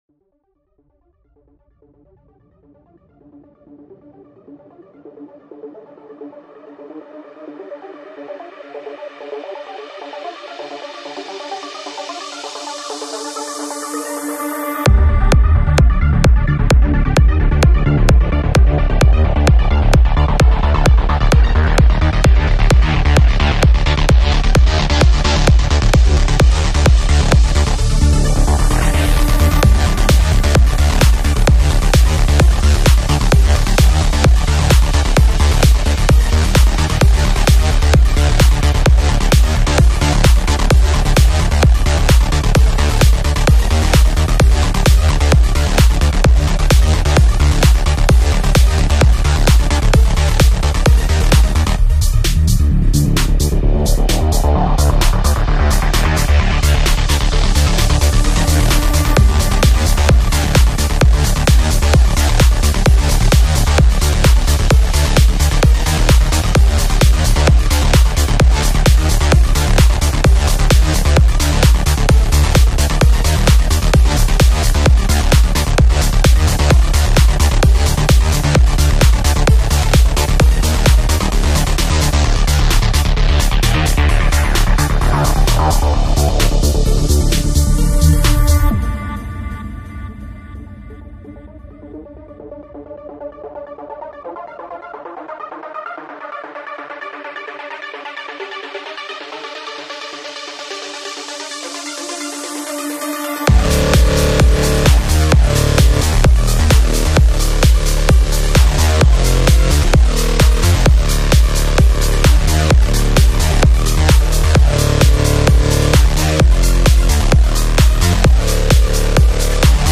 Industrial music : SECRET mp3 format.